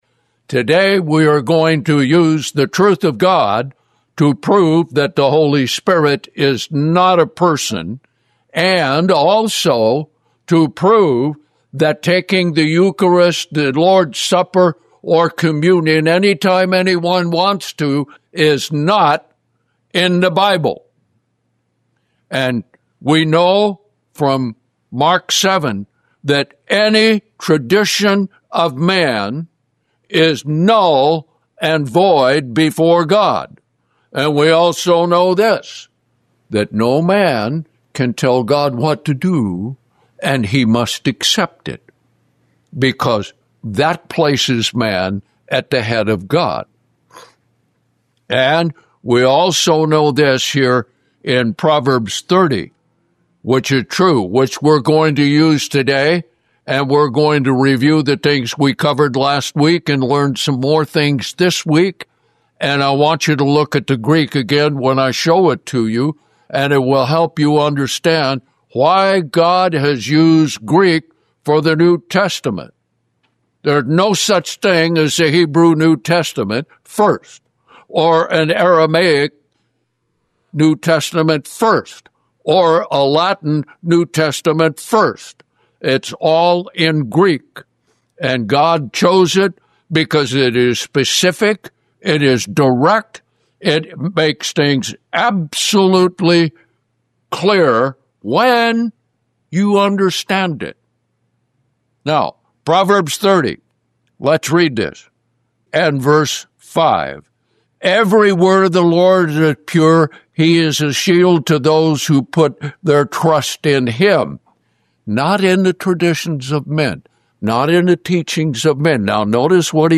Another lesson in Greek